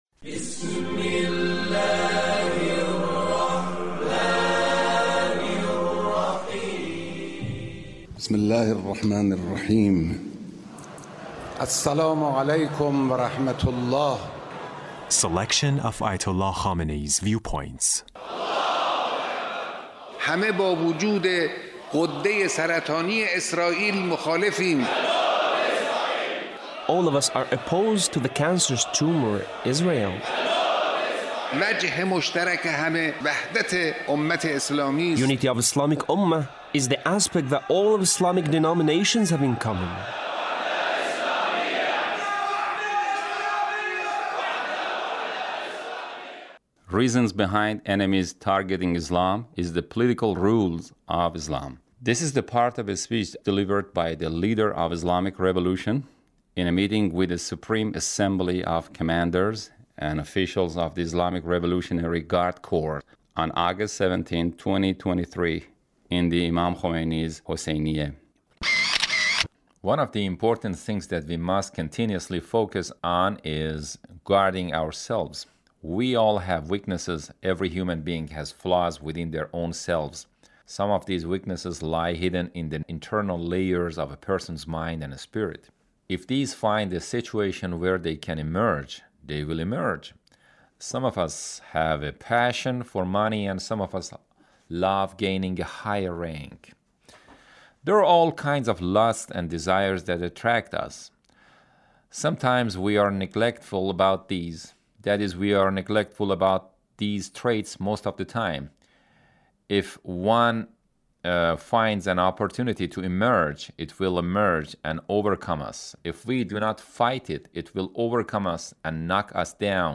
Leader's Speech (1815)
Leader's Speech in a meeting with Revolution in a meeting with the Supreme Assembly of Commanders and Officials of the Islamic Revolutionary Guard Corps (...